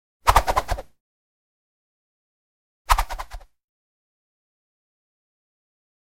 1. Свист и гул летящего томагавка n2. Визг и шелест полета томагавка